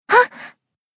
BA_V_Tsukuyo_Battle_Shout_2.ogg